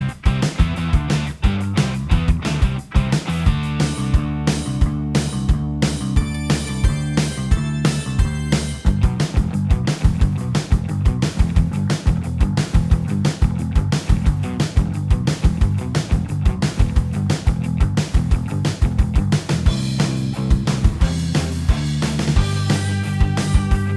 Minus All Guitars Except Rhythm Punk 3:46 Buy £1.50